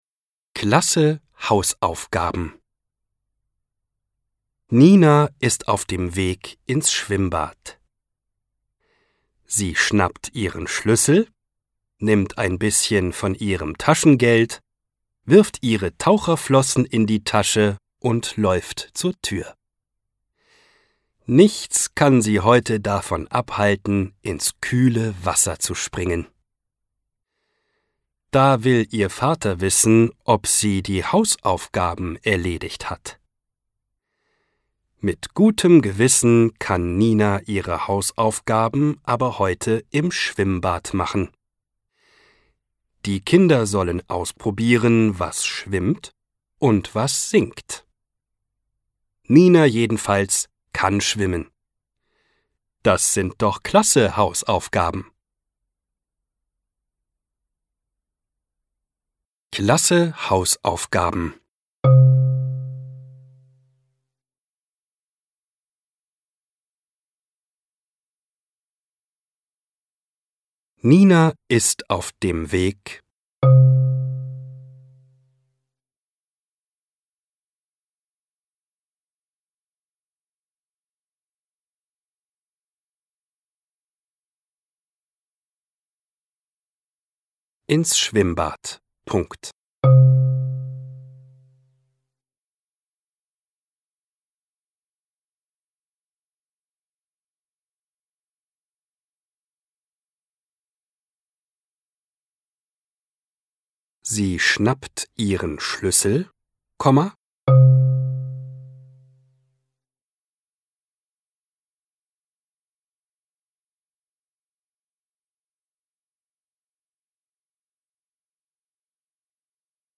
Duden | 150 Diktate – 2. bis 4. Klasse: Klasse Hausaufgaben